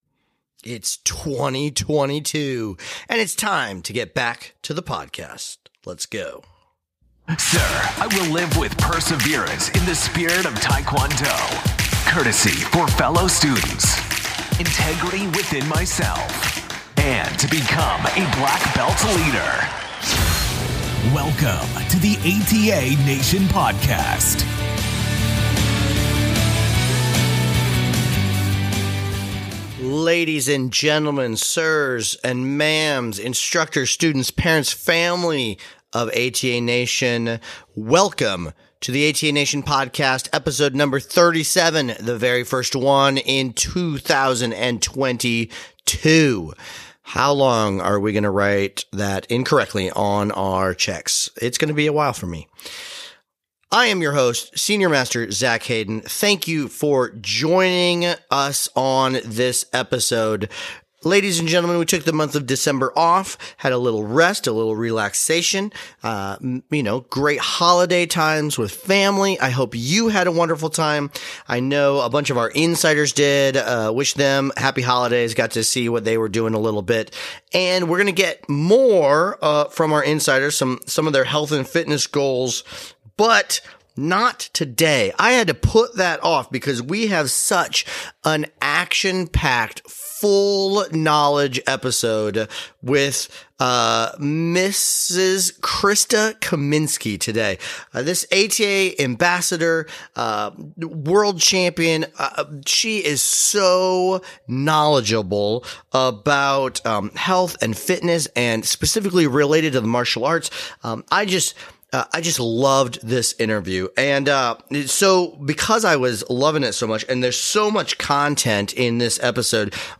Today's episode features an awesome interview with ATA World Champion